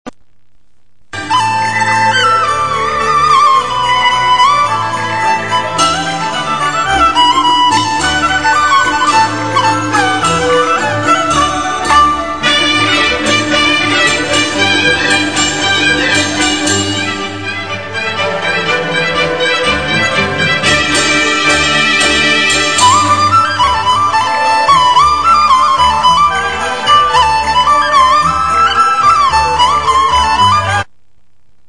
Erhu
Pipa
Suona
Banhu